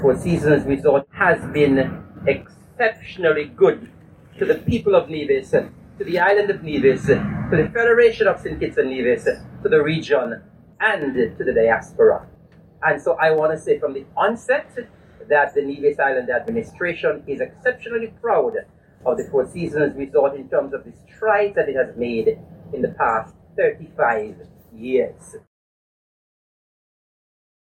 Deputy Premier, the Hon. Eric Evelyn, said the Resort has been good.